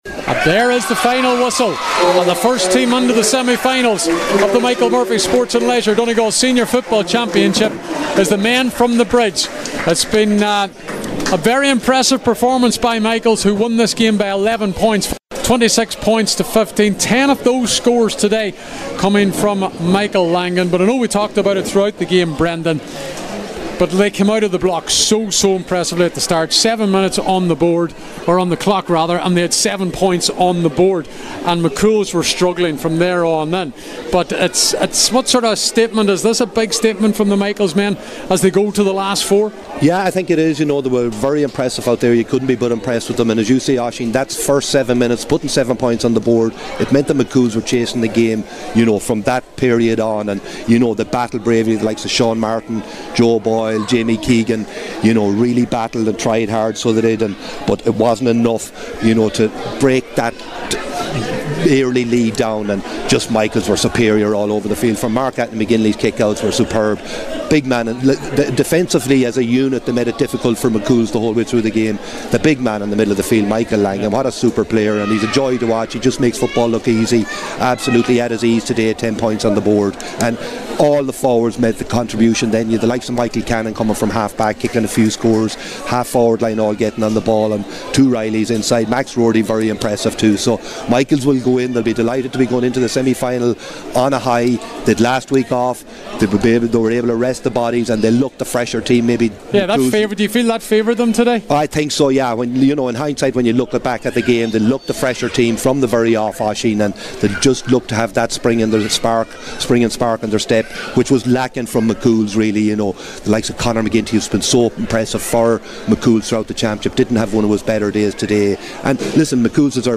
the full time report…